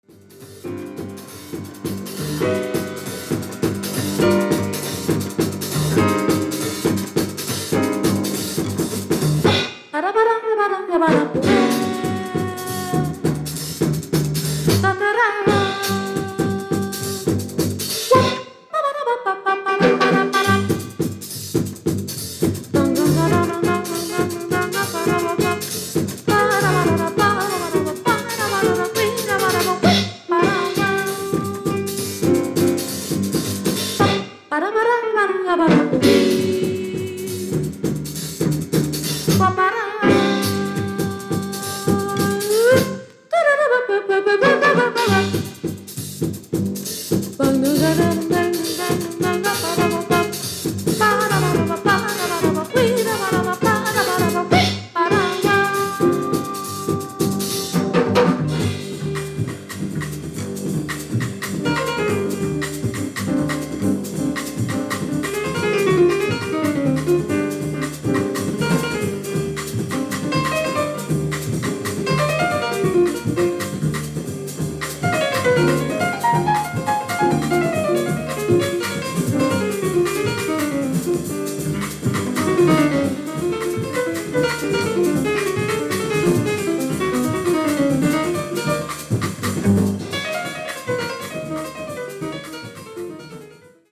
Jazz Vocal